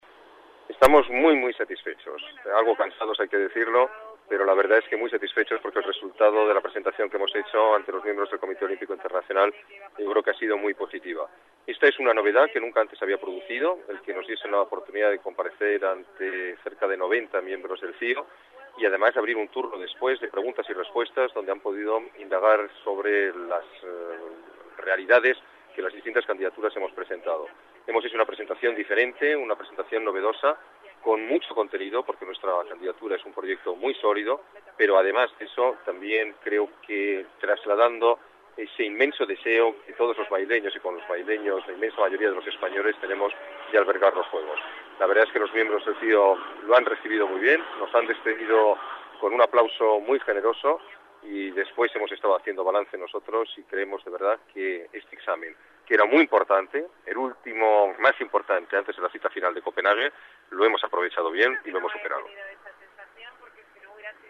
Nueva ventana:Valoración del alcalde sobre la presentación de la candidatura madrileña ante el CIO